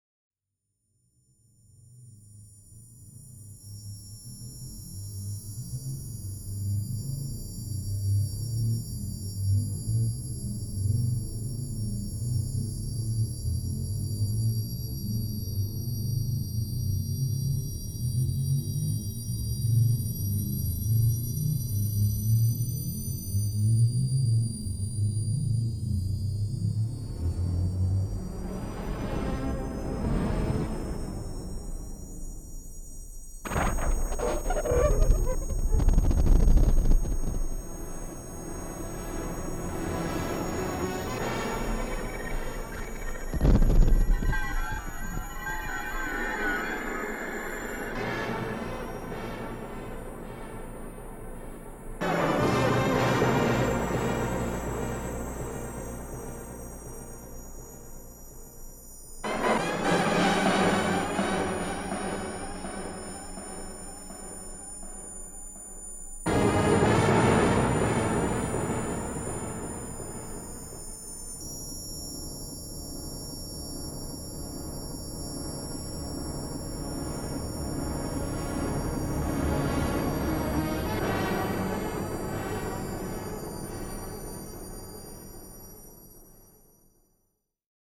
L’électroacoustique